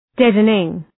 Προφορά
{‘dedənıŋ}